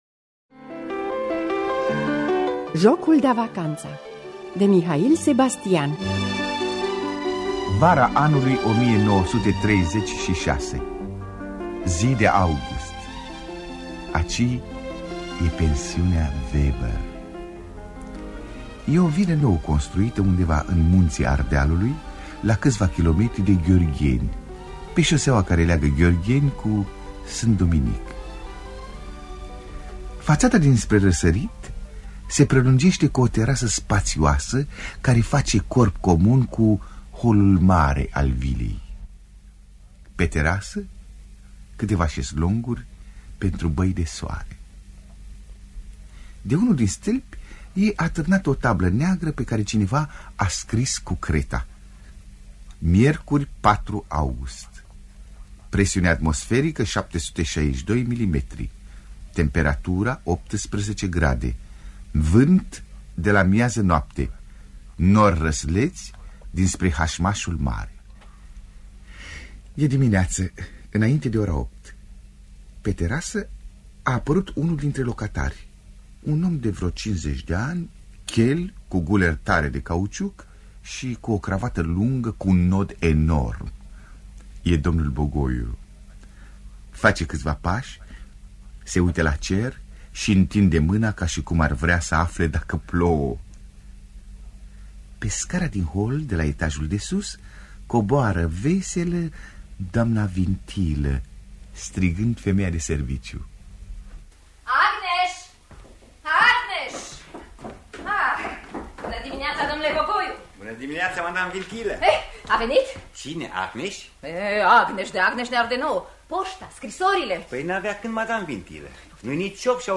Adaptarea radiofonică
Înregistrare din anul 1957.